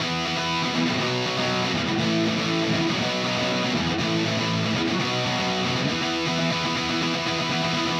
Power Pop Punk Guitar 01b.wav